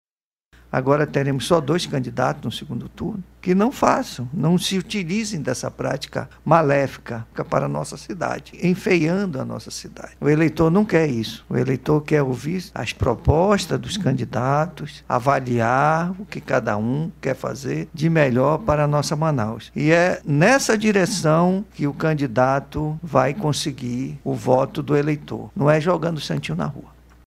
De acordo com os números divulgados, durante a coletiva de imprensa, nas primeiras cinco horas do pleito, 20 urnas apresentaram problemas, sendo que 11 precisaram ser substituídas.
Apesar de tais situações, o desembargador João Simões, presidente do Tribunal Regional Eleitoral do Amazonas, destaca que o pleito aconteceu dentro da normalidade, em todo o estado.